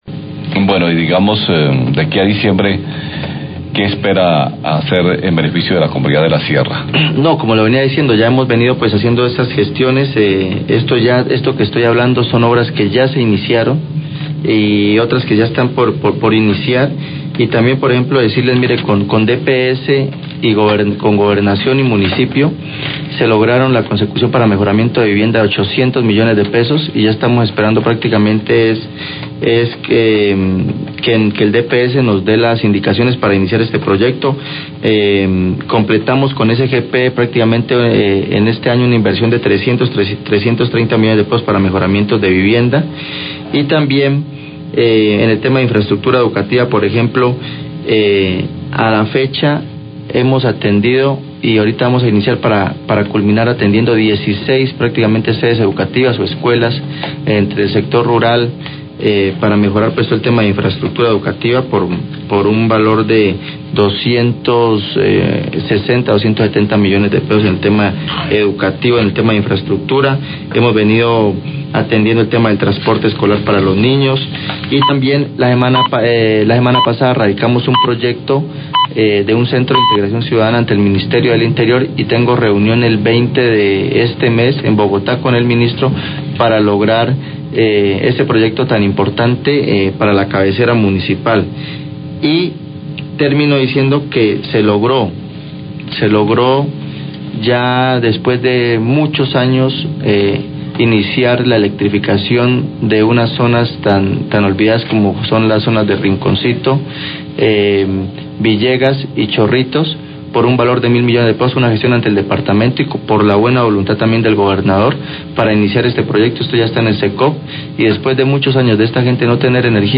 Radio
Jesús Eduardo Trochez, Alcalde de La Sierra, informa que ya inició el proyecto de electrificación de zonas olvidadas del municipio como Rinconcito, Villegas y Chorritos, por un valor de 1000 millones de pesos, beneficiando a 300 familias; falta la electrificación en Puerto Grande Alto de la Jagua.